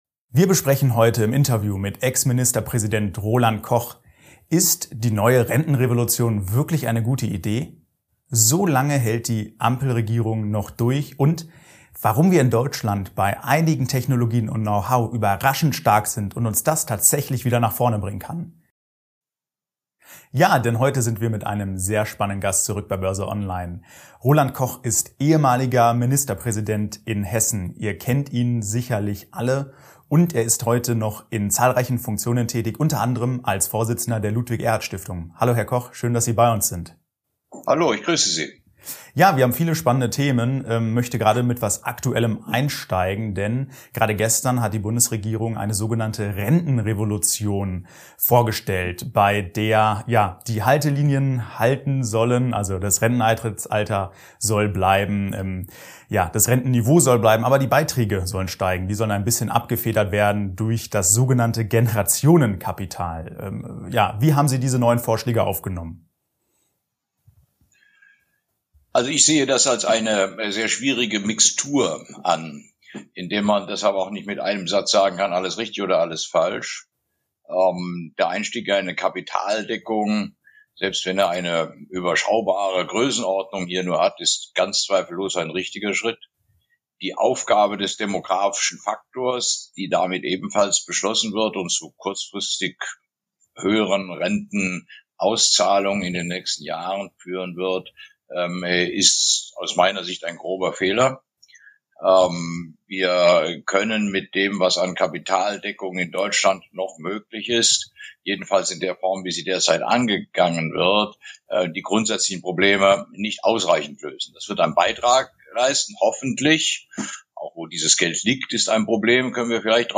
Ist diese Renten-Revolution in Deutschland sinnvoll? BÖRSE ONLINE im Talk mit Ex-Ministerpräsident Roland Koch ~ BÖRSE ONLINE Podcast